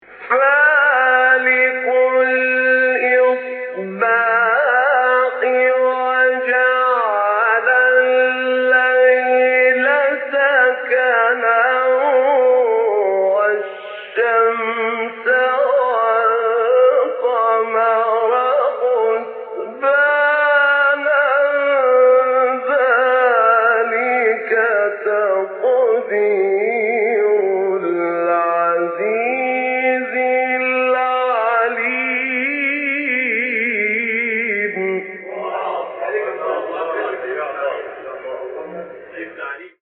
مقام رست سوره انعام شیخ طاروطی | نغمات قرآن | دانلود تلاوت قرآن